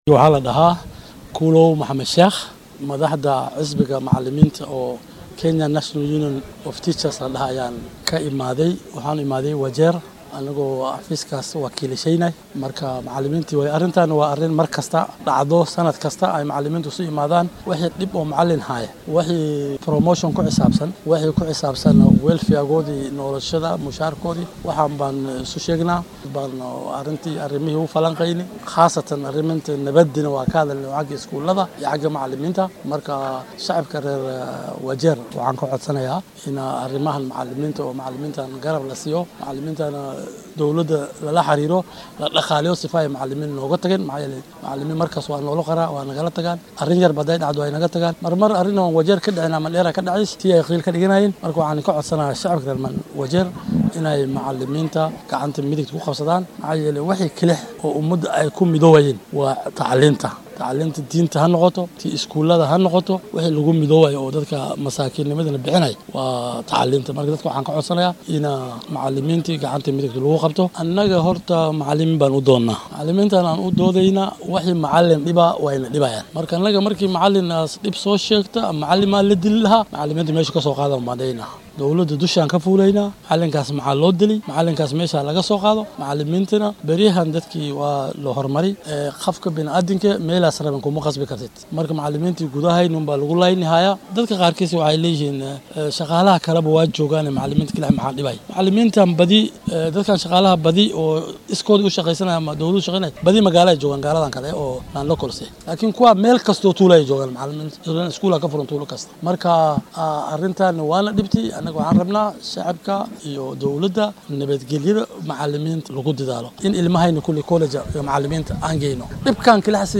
Qaar ka mid ah mas’uuliyiintii kulankan ka qayb galay ayaa warbaahinta la hadlay